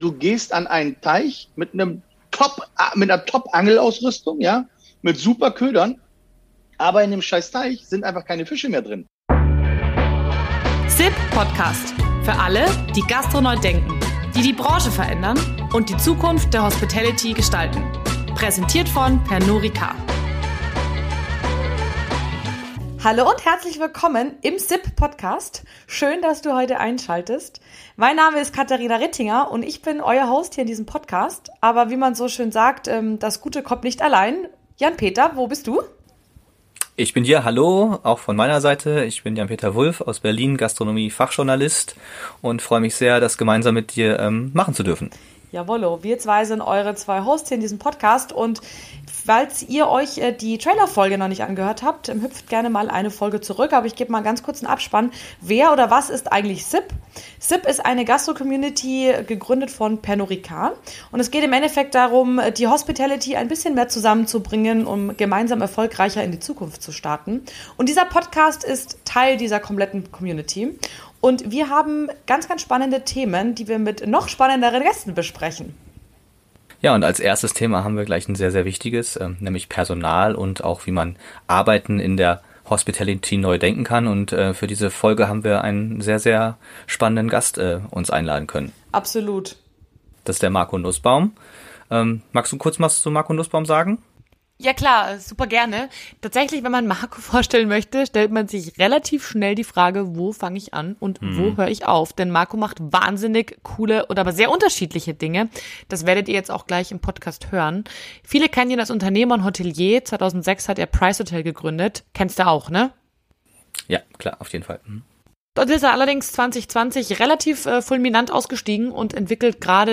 informatives und mitreißendes Interview mit Hotelier